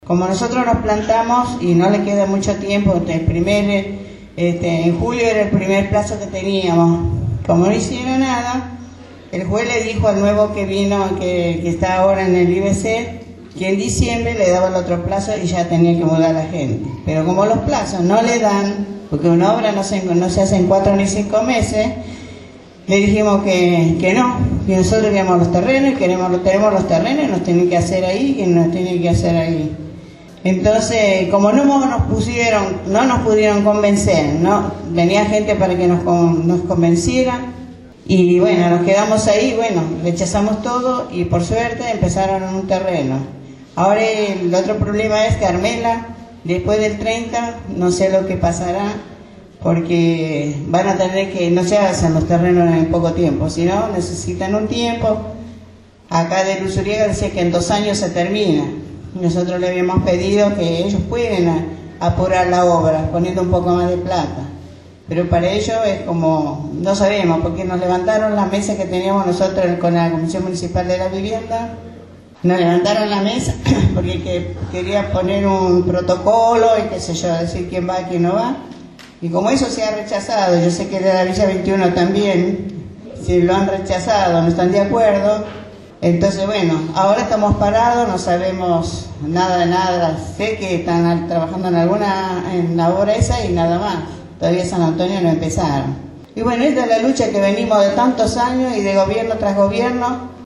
El sábado 18 de agosto en la escuela Lafayette de Barracas se realizó un encuentro por el conflicto por la relocalización de las familias que viven en el camino de sirga.